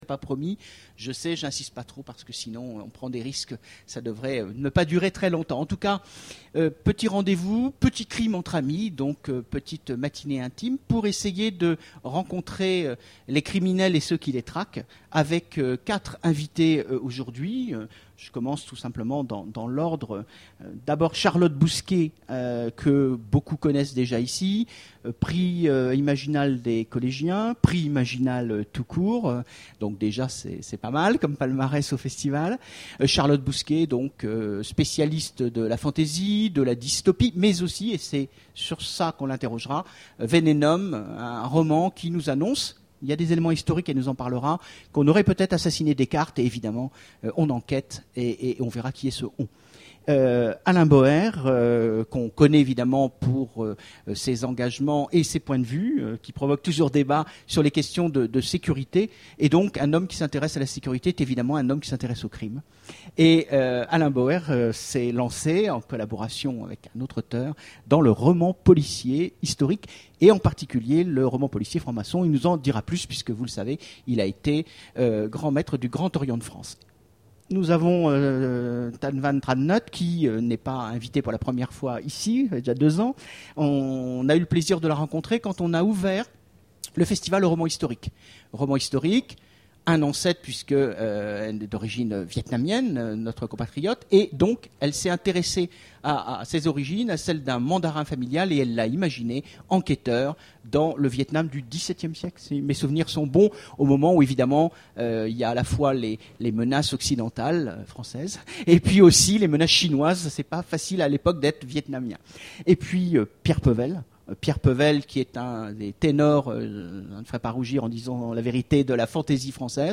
Imaginales 2013 : Conférence Romans historiques...